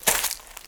High Quality Footsteps
STEPS Leaves, Walk 10.wav